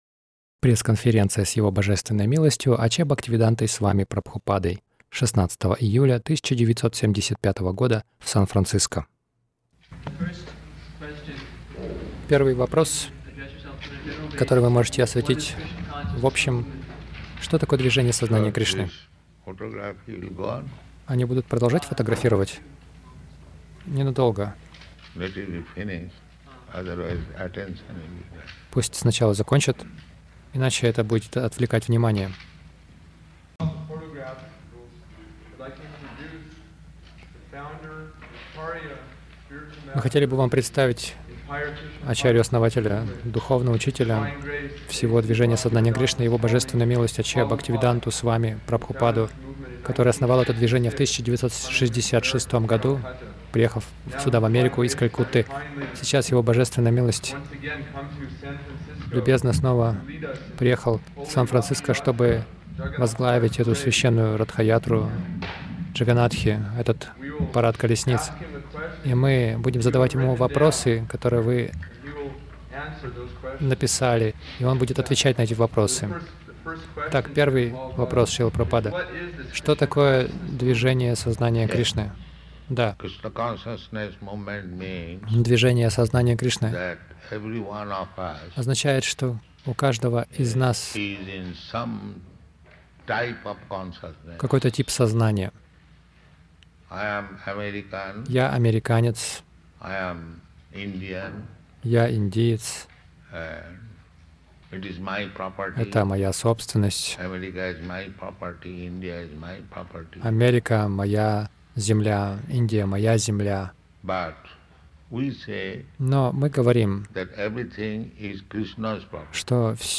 Пресс-конференция — Ратха-Ятра в Америке